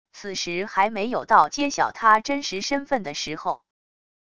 此时还没有到揭晓他真实身份的时候wav音频生成系统WAV Audio Player